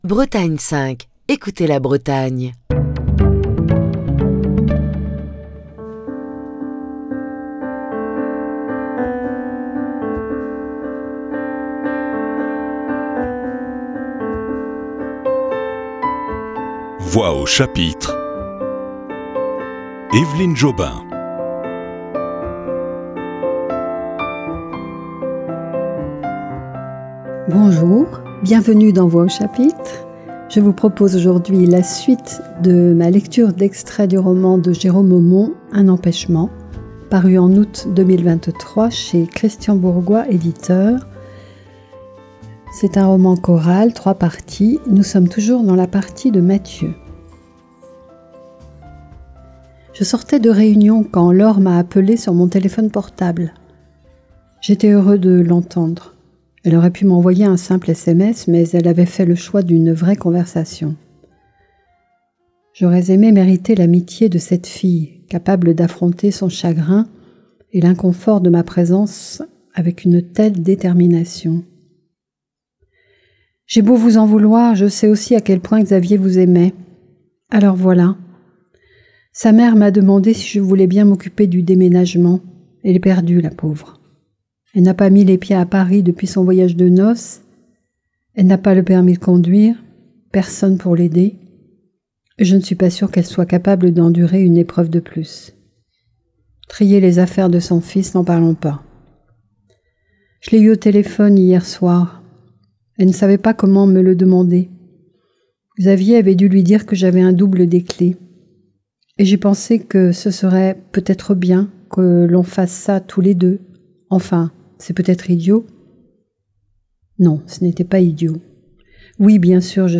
la lecture d'extraits du roman de Jérôme Aumont